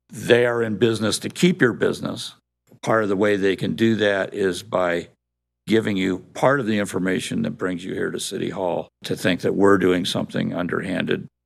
Here is council member Terry Urban.